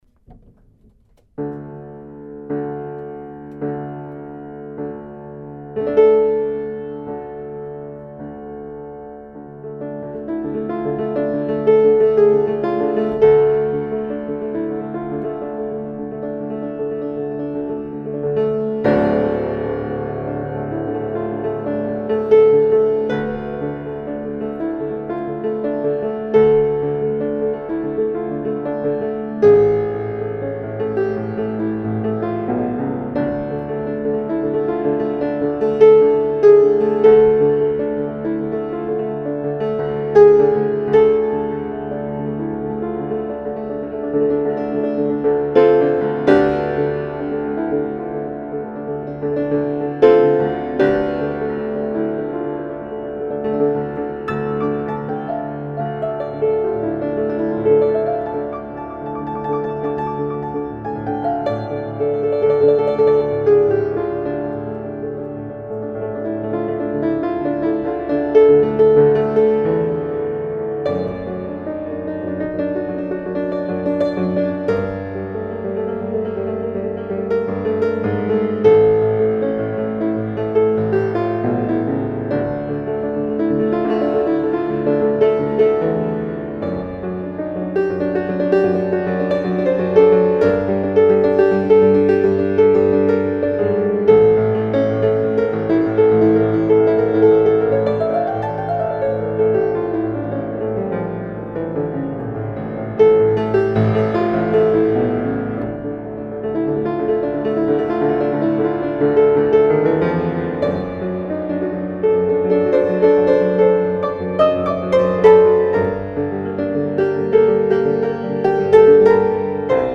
יצירה שהעליתי פה בעבר אך הפעם היא באיכות יותר טובה וגם יש הוספות ושיפורים ליצירה. ההקלטה היא ממכשיר של מישו שמקליט לאולפן שיש לו, זה בקונצרט של המורה שלי לפסנתר וגם אני ניגנתי קצת.